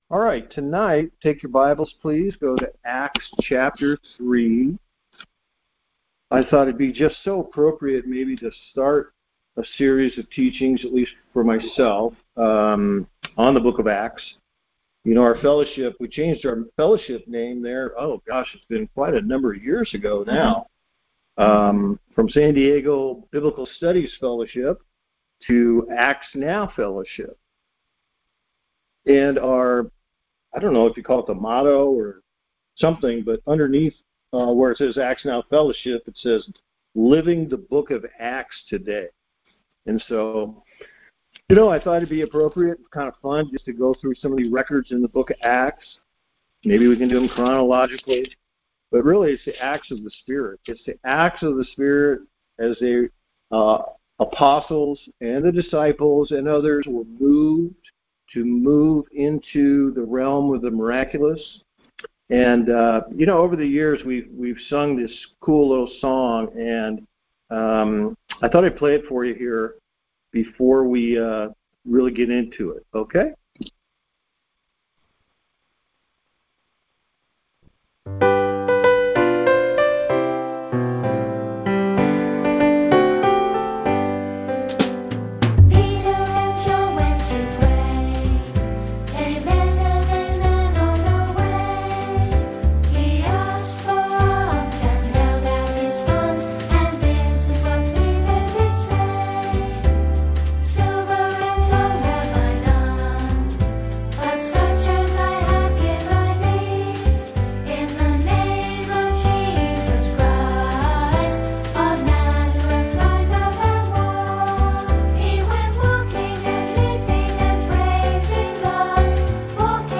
Church in San Diego California